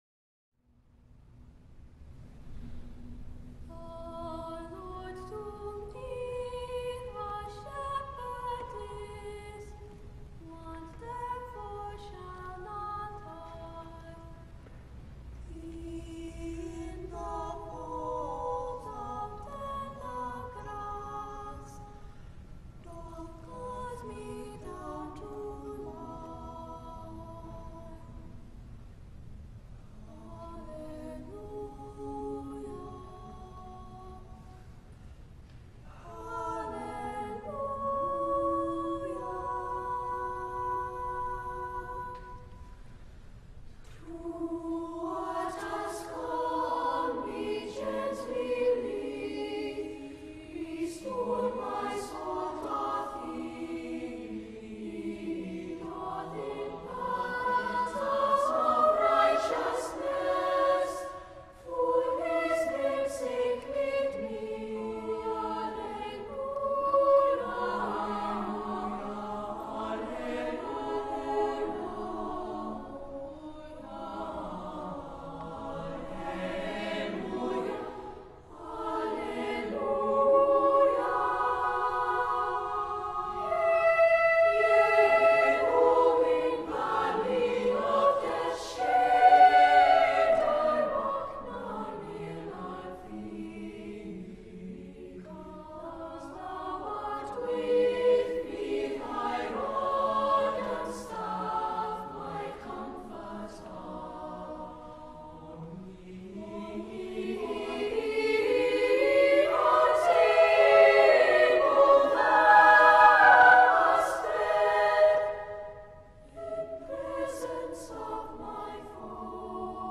SSA a cappella